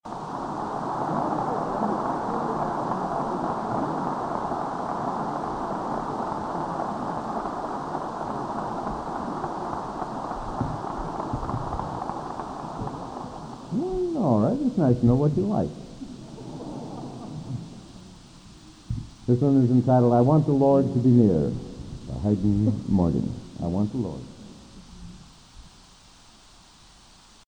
Collection: Broadway Methodist, 1982
Genre: | Type: Broadway Methodist